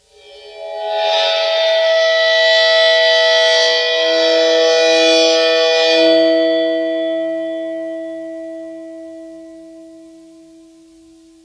susCymb1-bow-3.wav